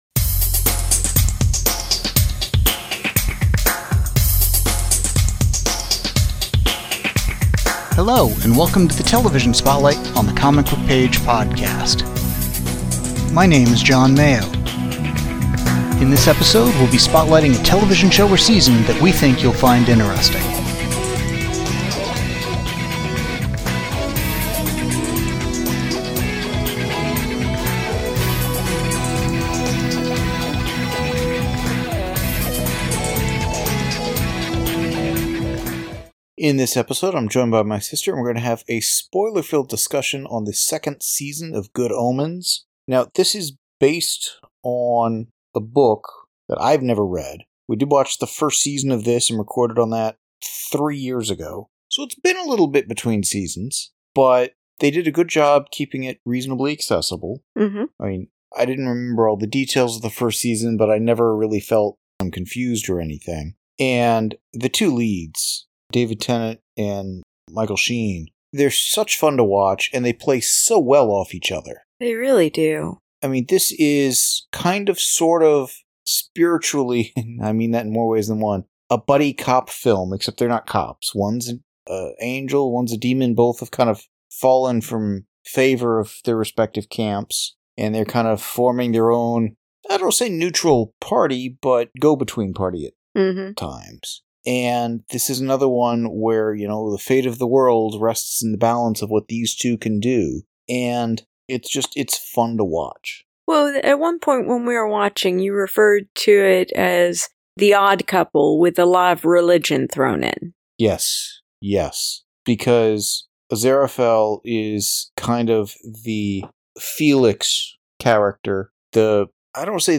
spoiler filled discussion